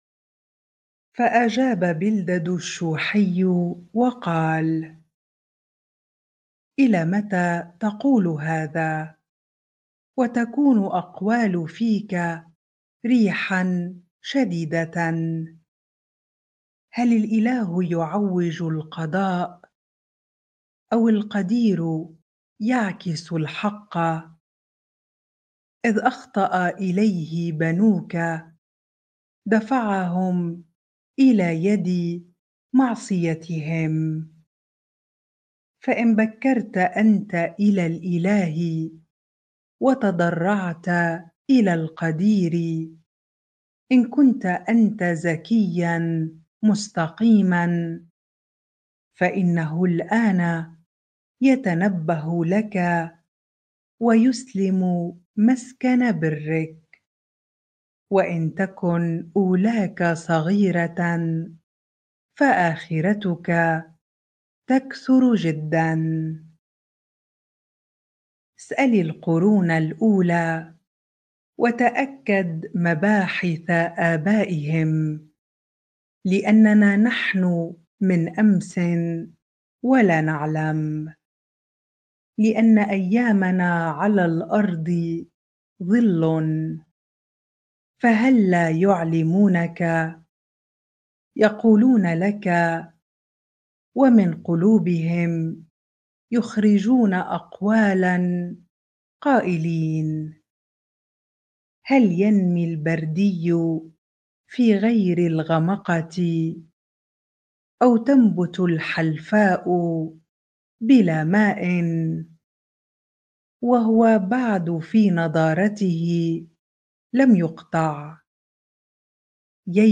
bible-reading-Job 8 ar